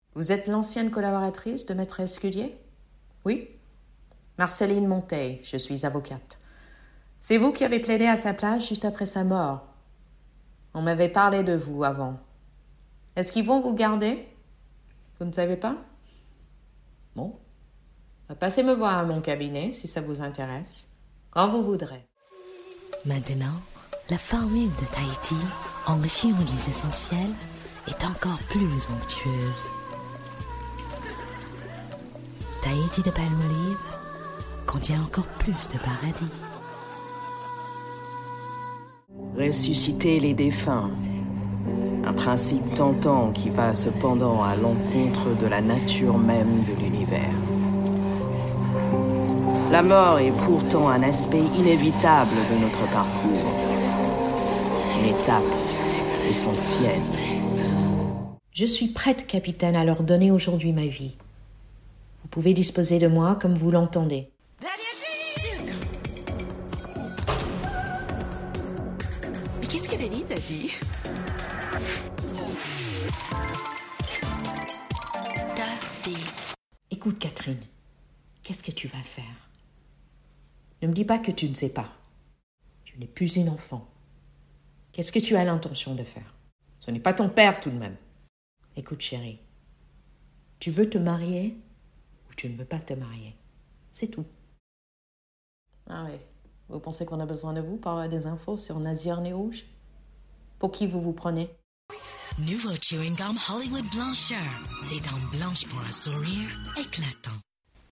SPOKEN VOICE REEL - FRANÇAIS 082024